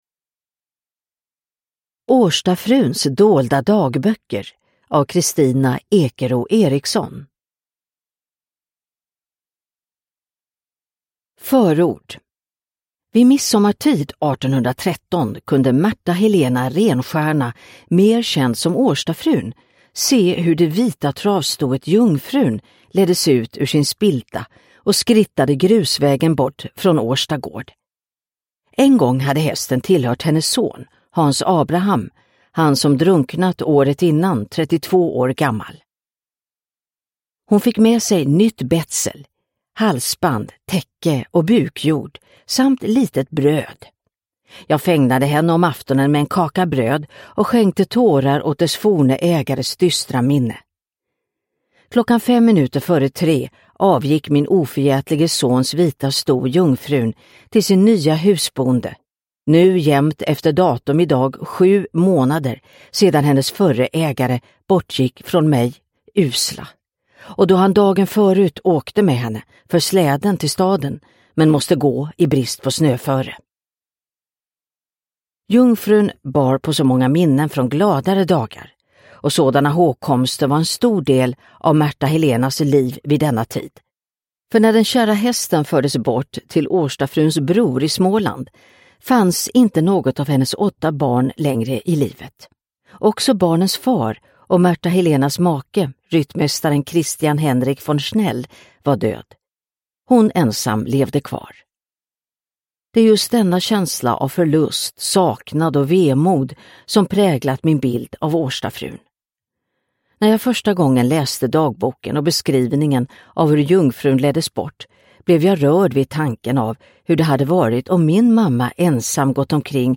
Årstafruns dolda dagböcker – Ljudbok – Laddas ner